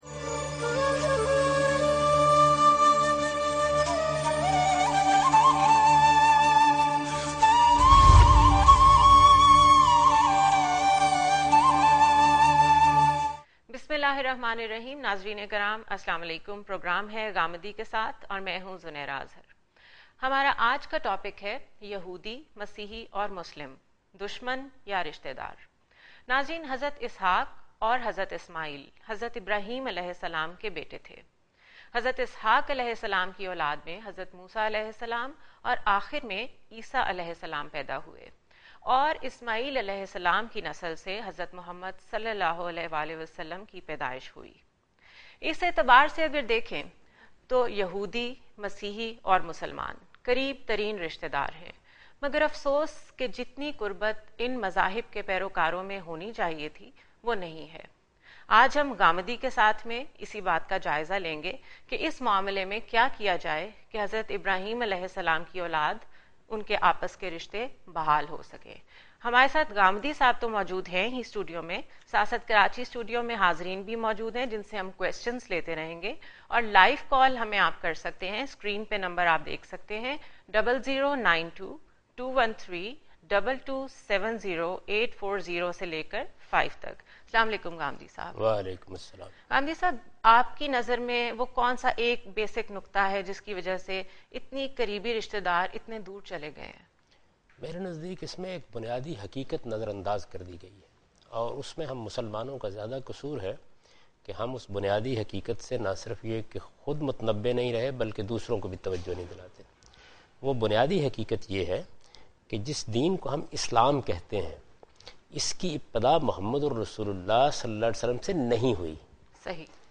In this program hostess raises important questions on the topic 'Muslims, Christians and Enemies are Relatives or Enemies'.Program